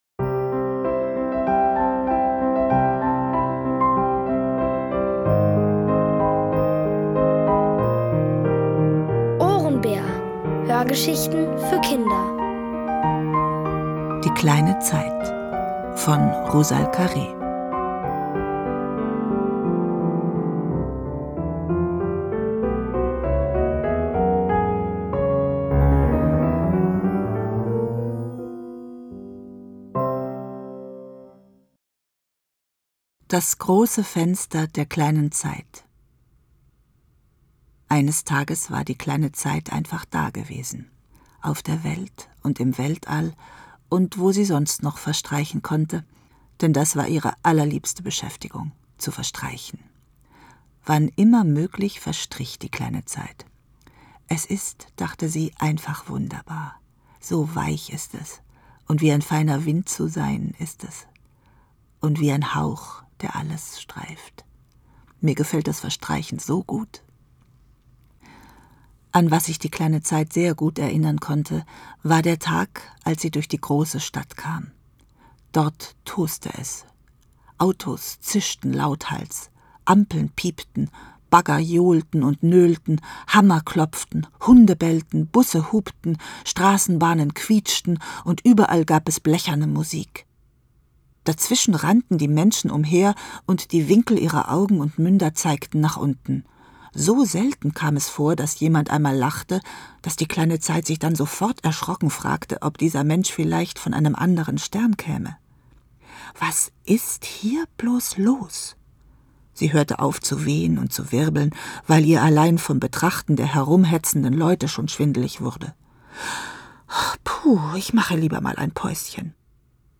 Es liest: Martina Gedeck.